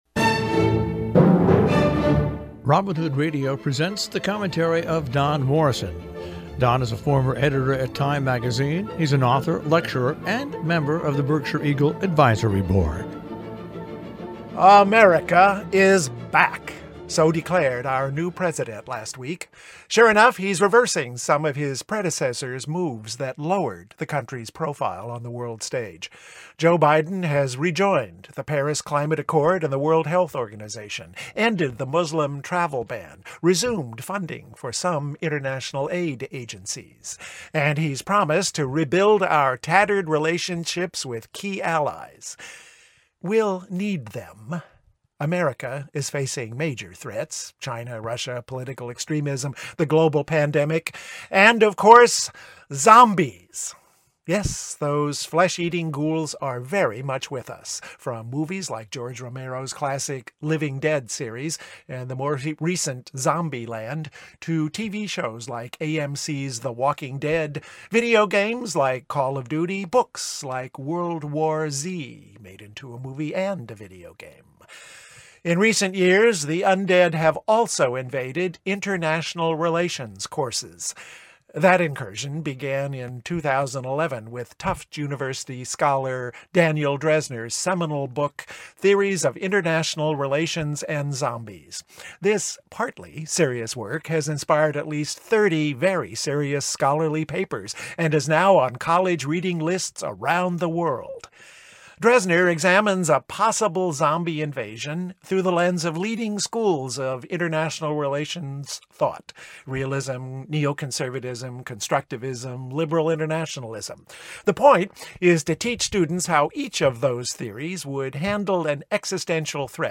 COMMENTARY